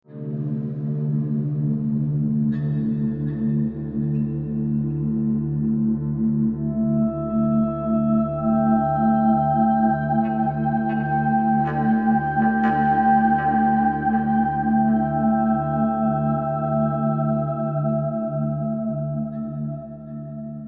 无人机密集型
描述：黑暗的张力无人机